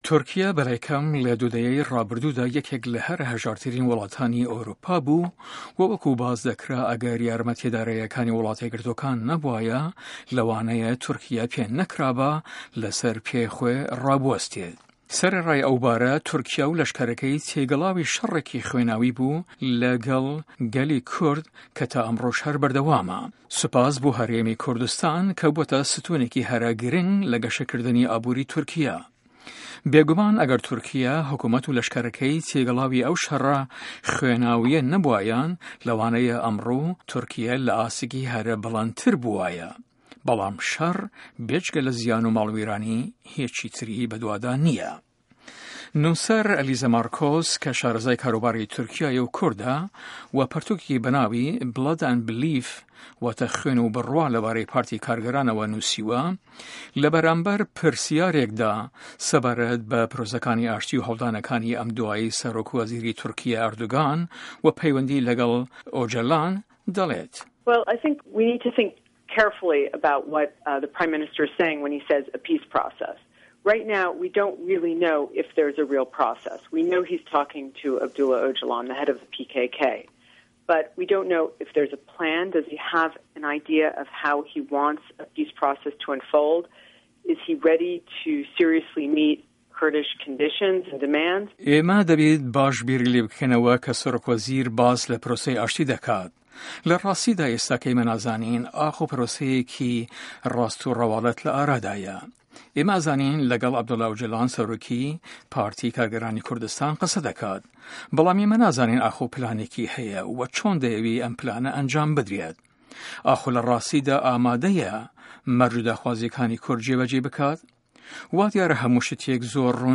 له‌م هه‌ڤپه‌ێڤینه‌دا تێشک ده‌خاته‌ سه‌رپرۆسه‌کانی ئاشتی ئه‌م دواییه‌دا له‌ نێوان کورد و حکومه‌تی تورکیا .